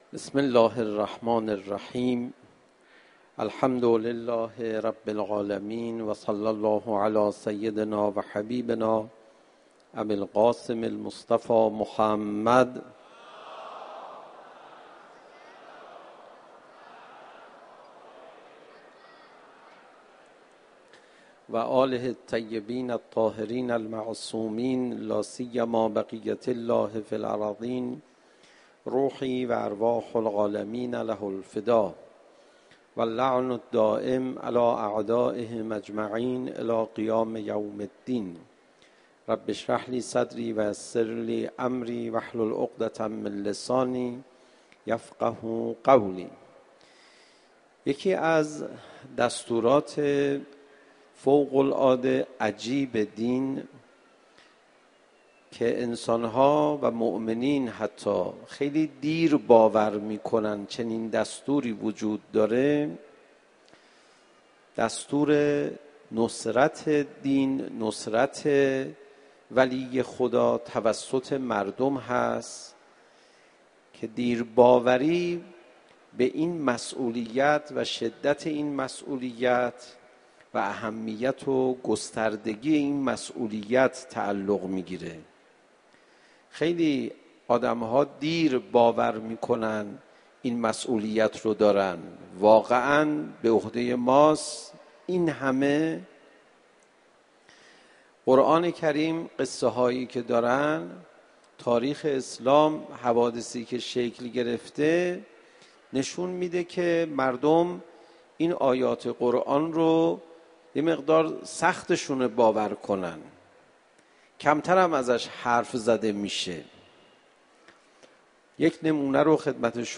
صوت کامل سخنرانی حجت الاسلام والمسلمین پناهیان در سالروز شهادت حضرت زهرا(س) حرم مطهر رضوی